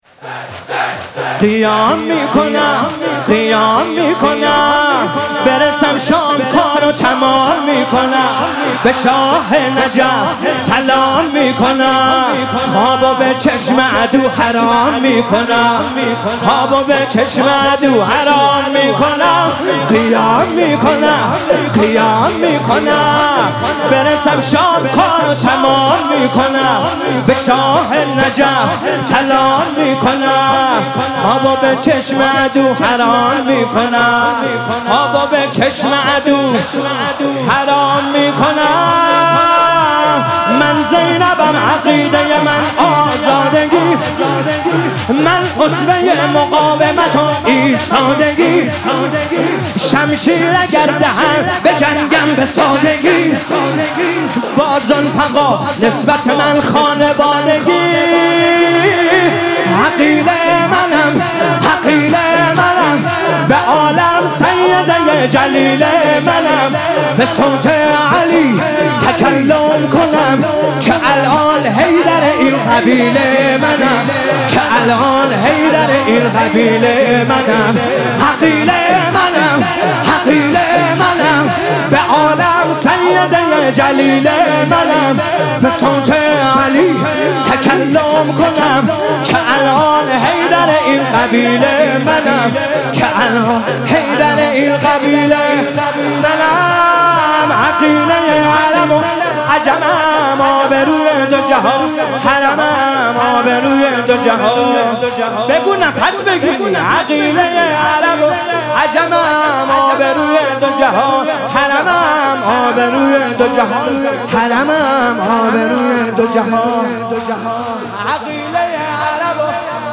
نوحه
مداحی پر شور